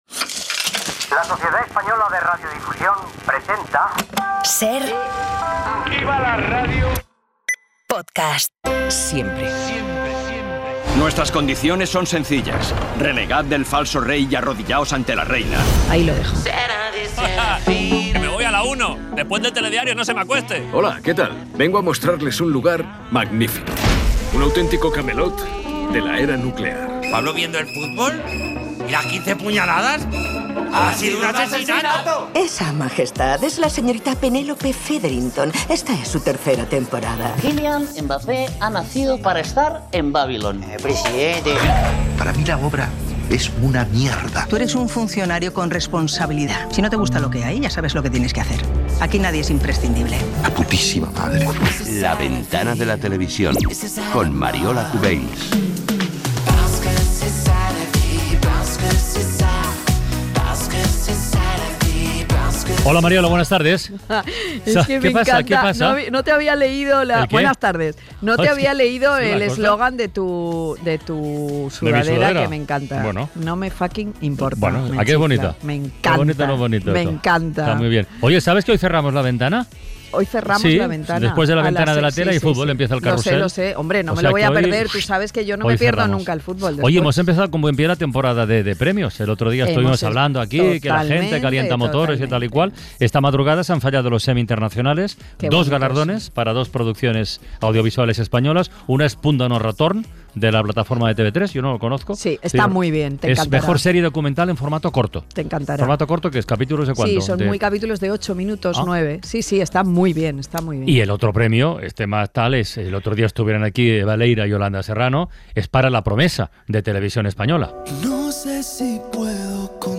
En La Ventana de la Tele de esta semana charlamos con Henar Álvarez, presentadora y co-directora de "Al cielo con ella", un late show semanal de RTVE Play que combina entrevistas y humor gamberro e irreverente.